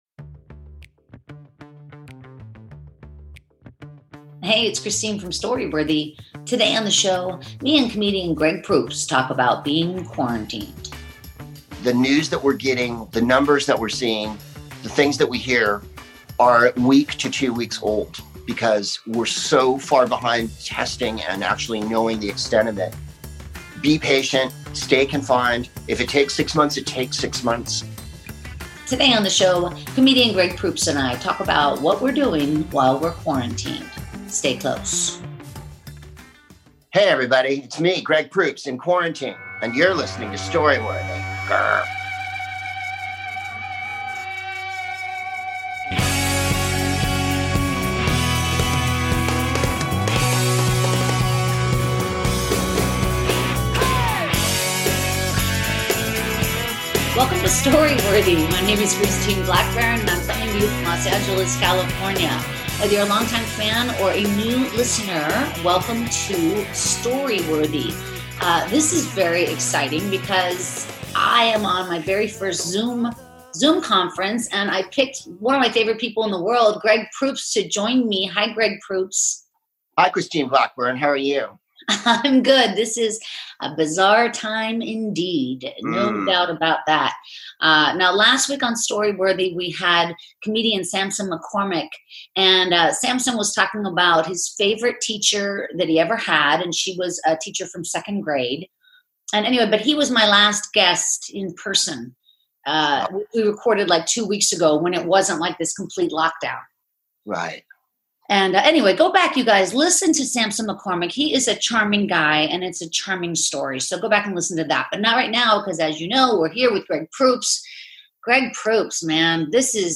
Comedian Greg Proops (The Smartest Man in the World, Whose Line Is It Anyway?